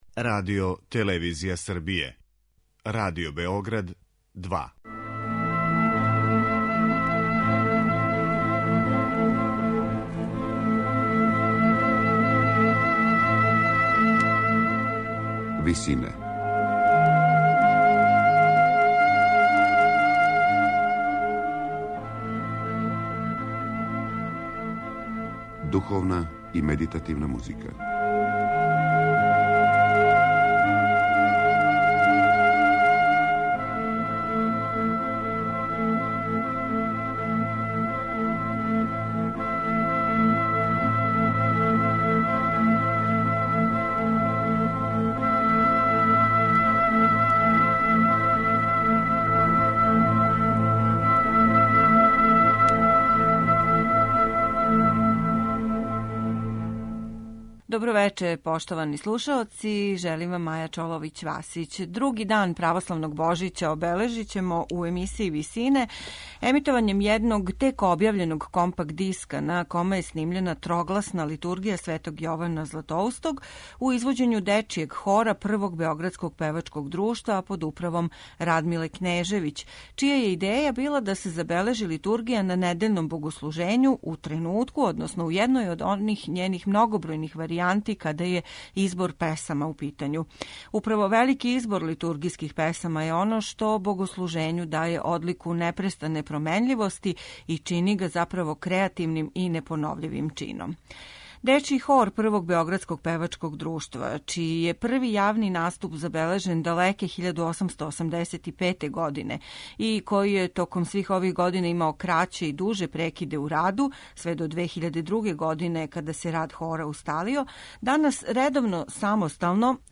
народне напеве аранжиране за трогласни хор